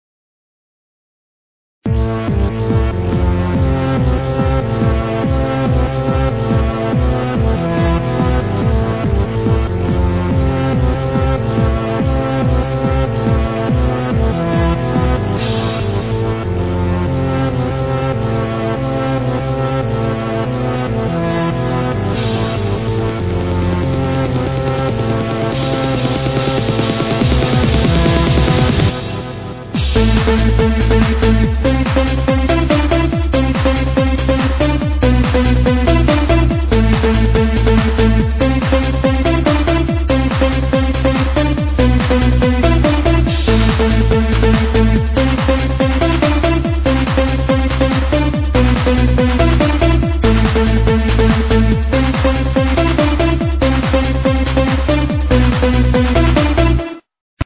cheesy